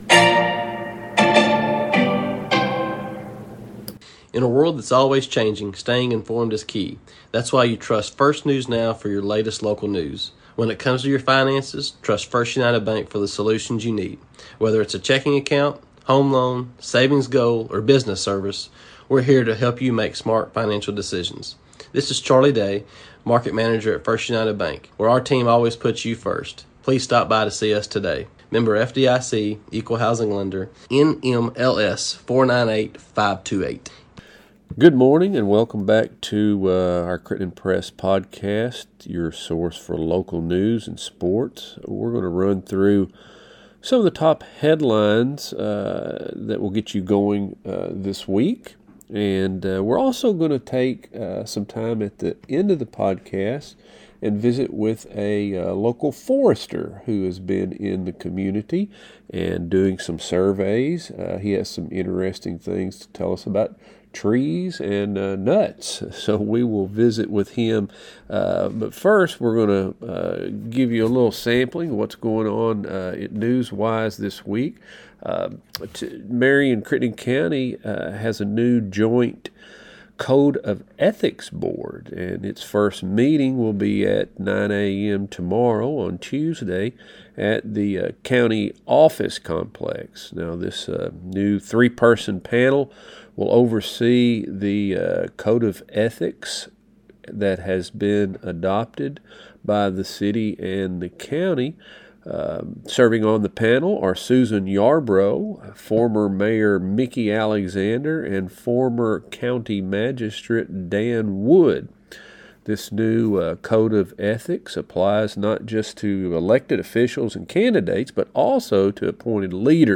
Local News | Sports | Interviews